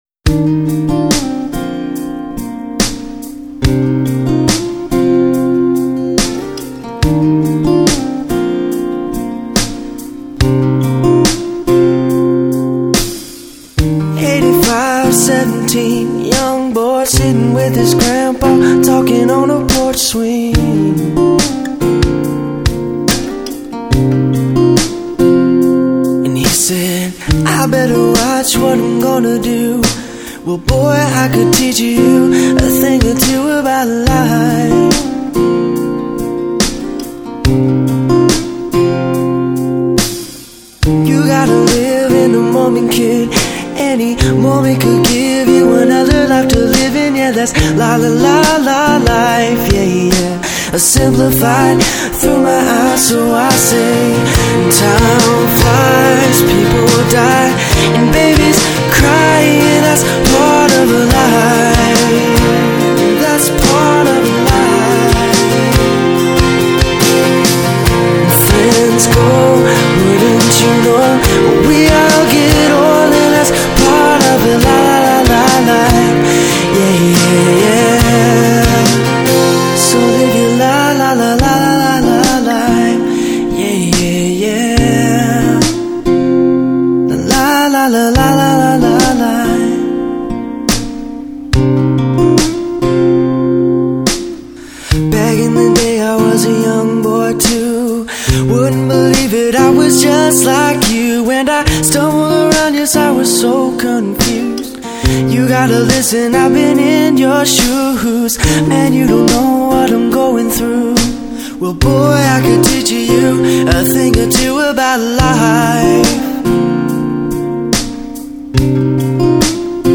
"Losing Life." (pop/rock)